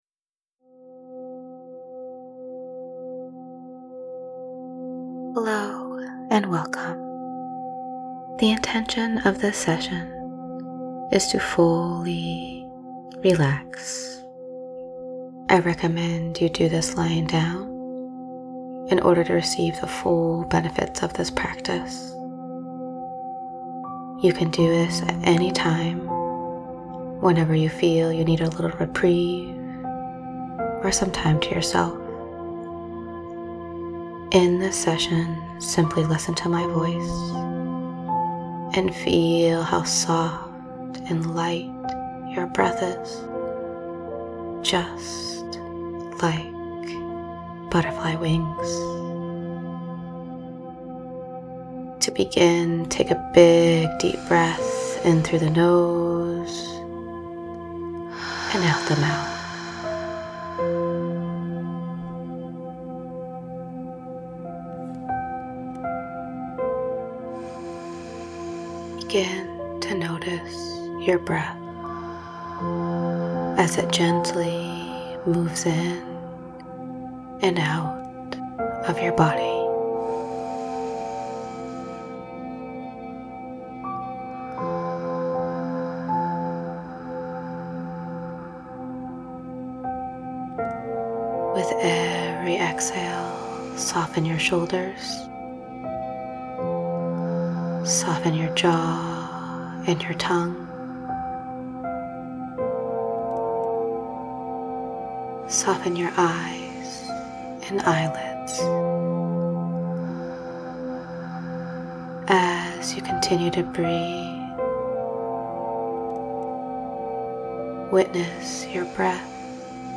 Heart-centered professionally guided Breathwork sessions to calm, clear, and energize.
Easy to follow breaths with intuitively chosen music.